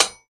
metalsnip2.ogg